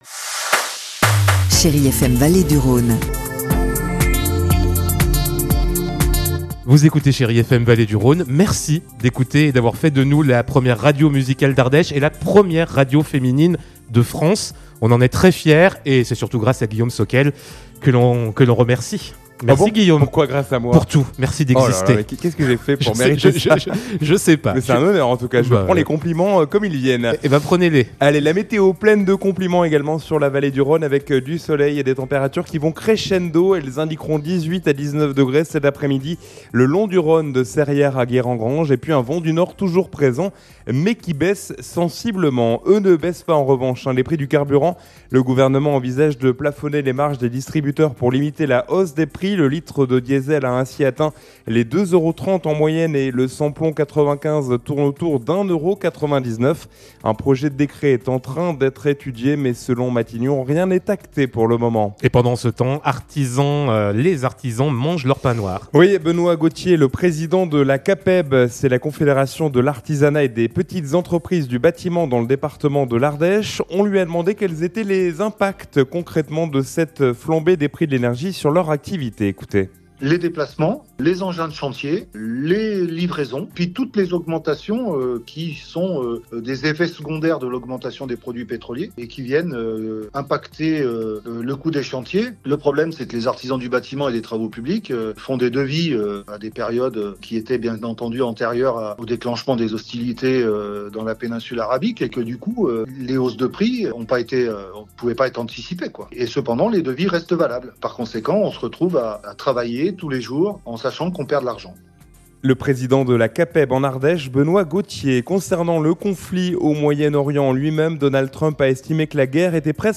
Mercredi 15 avril : Le journal de 12h